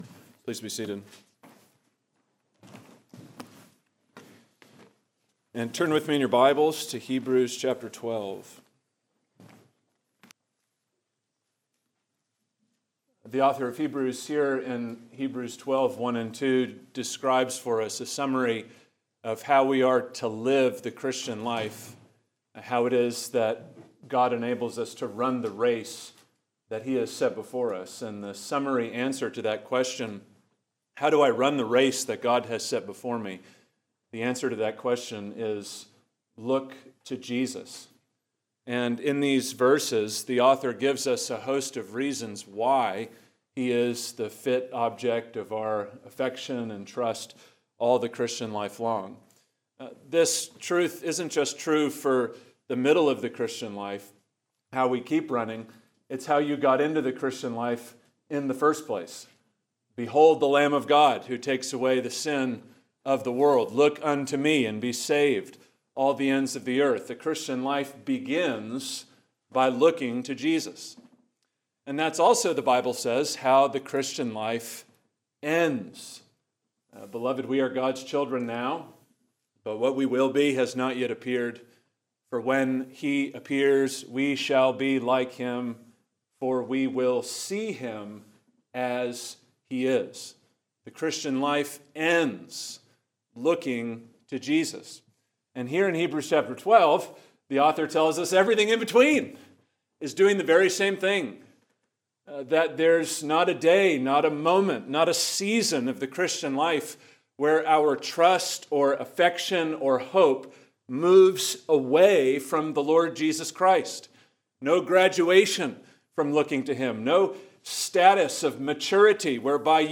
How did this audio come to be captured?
Chapel at RTS Jackson